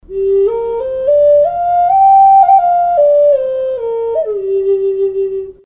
Пимак G Тональность: G